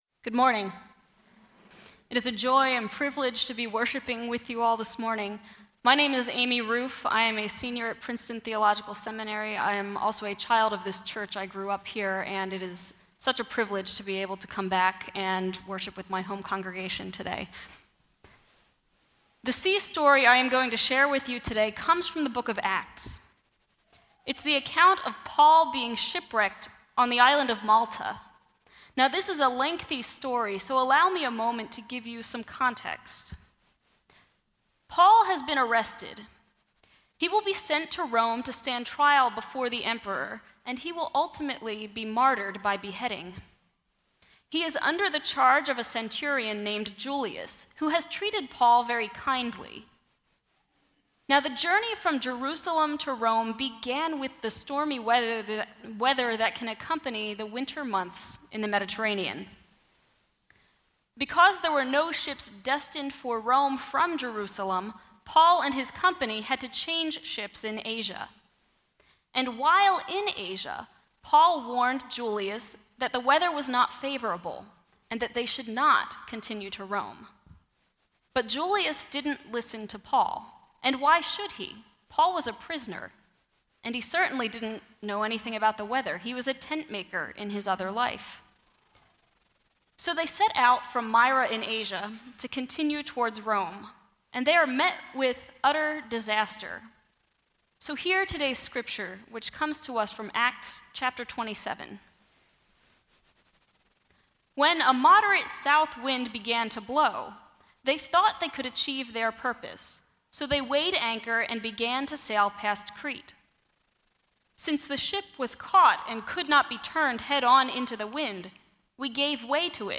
Preached at First Presbyterian Church of Ambler, August 19, 2018